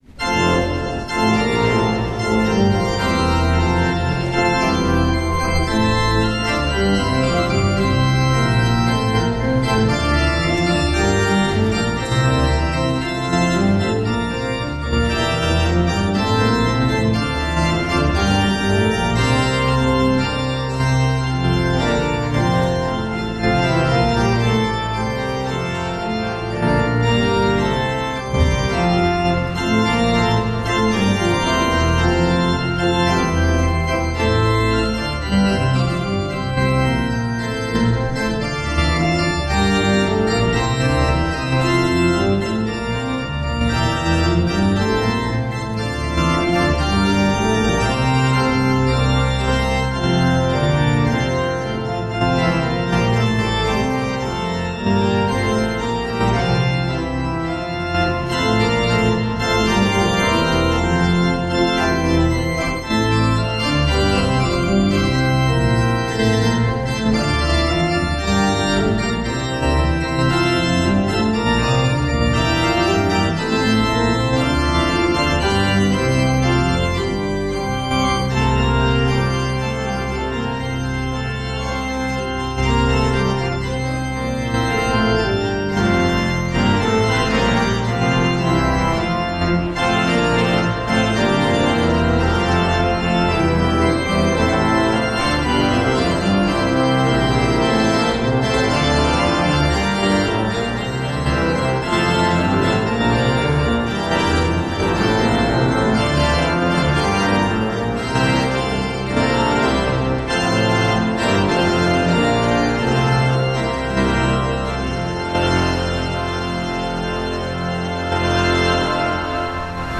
Hear the Bible Study from St. Paul's Lutheran Church in Des Peres, MO, from January 18, 2026.
Join the pastors and people of St. Paul’s Lutheran Church in Des Peres, MO, for weekly Bible study on Sunday mornings.